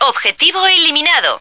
flak_m/sounds/female2/est/F2target.ogg at 46d7a67f3b5e08d8f919e45ef4a95ee923b4048b